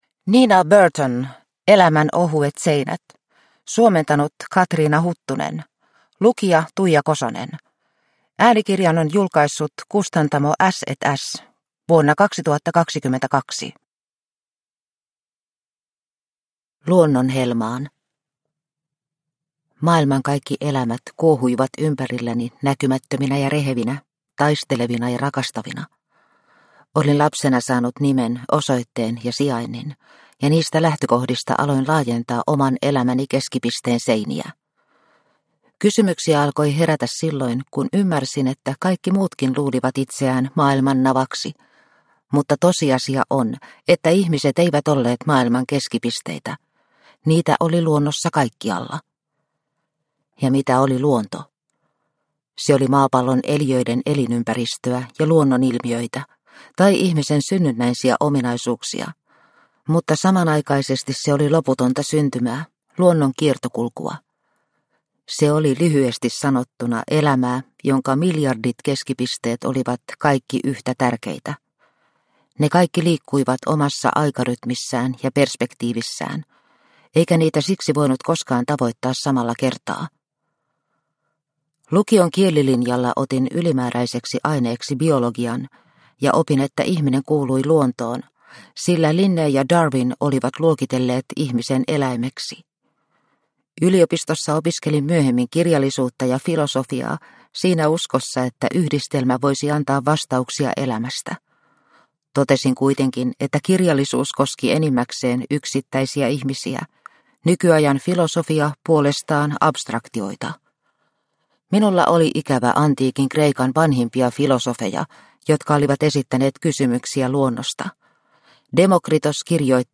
Elämän ohuet seinät – Ljudbok – Laddas ner